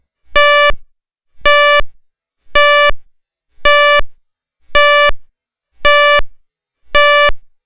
Provides up to 85 dB at 5 feet.
102/108 Beep Sound - 168.8K
beep.wav